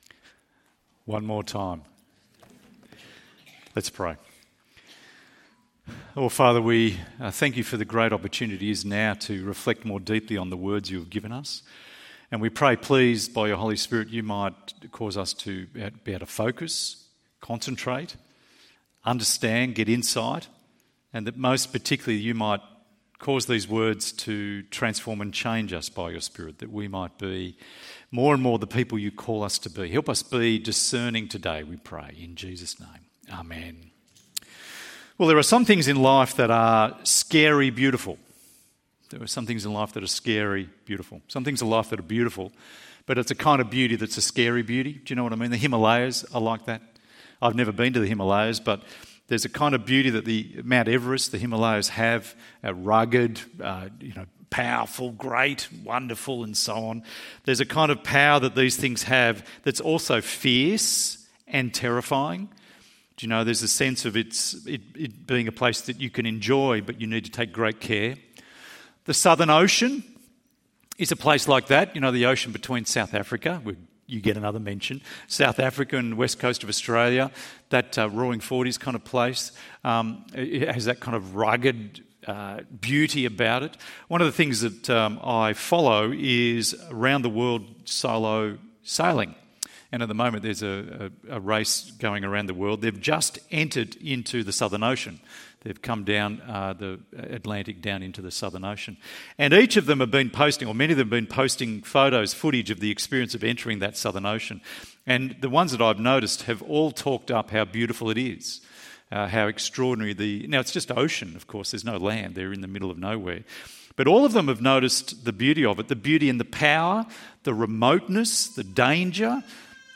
Children in the Son ~ EV Church Sermons Podcast